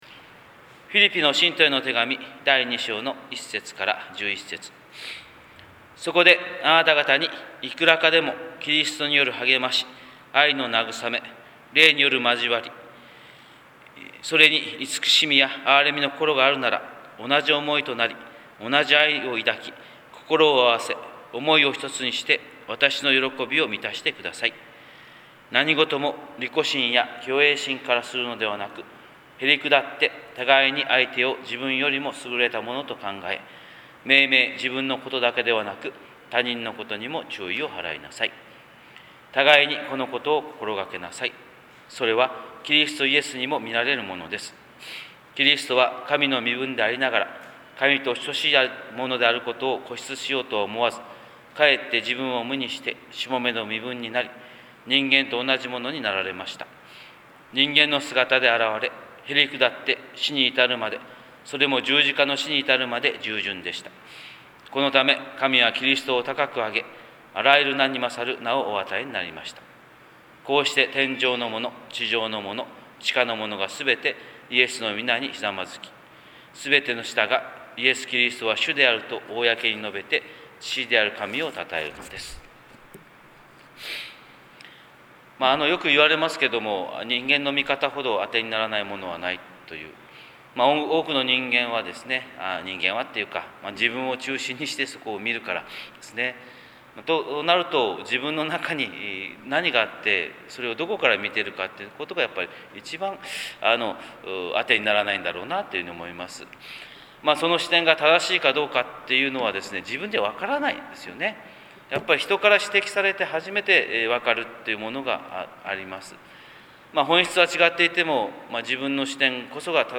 神様の色鉛筆（音声説教）
朝礼拝150202